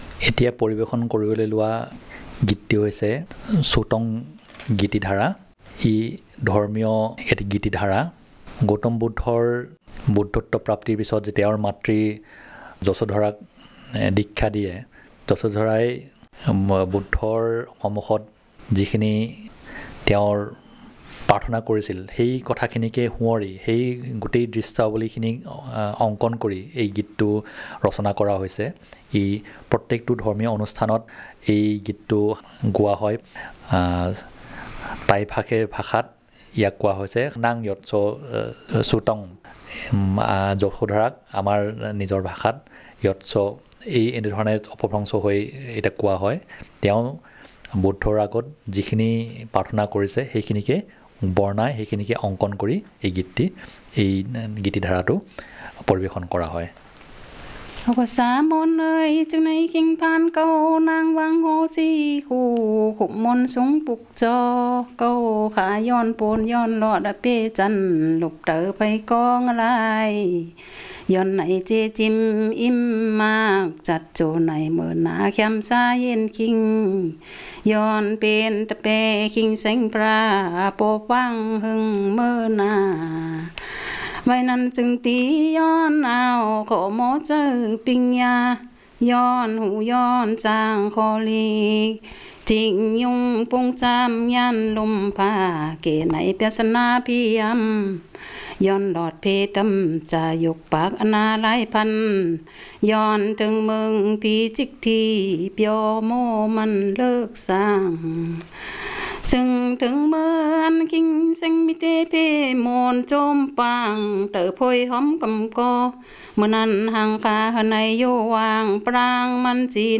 Performance of a song about religious activity of Buddhism